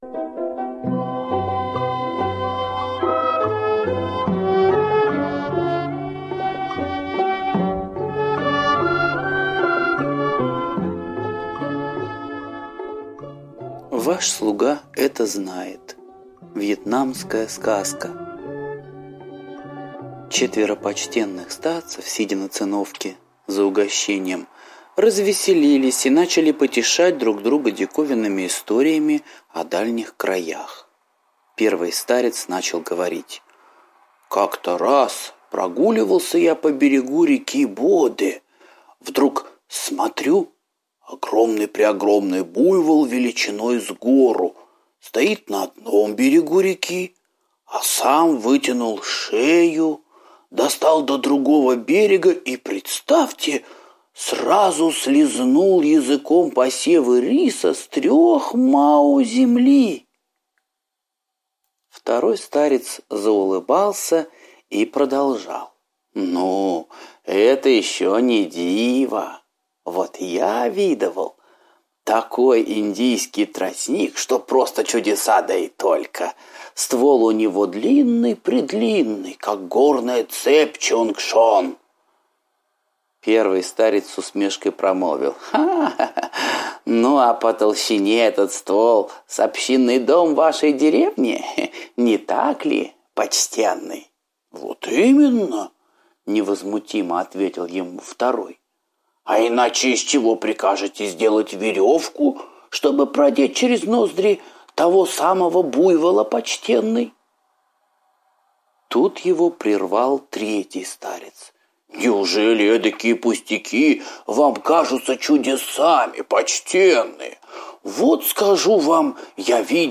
Ваш слуга это знает - восточная аудиосказка - слушать онлайн